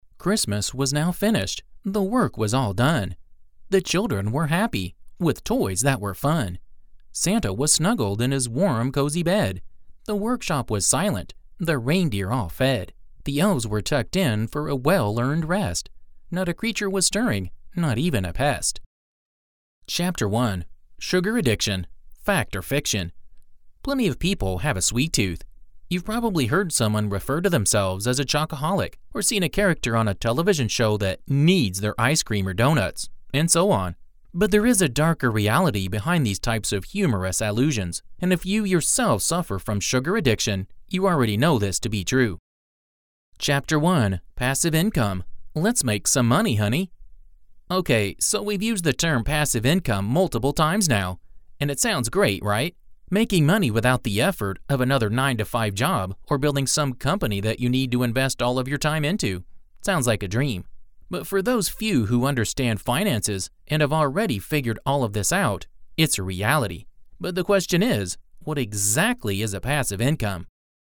电子书旁白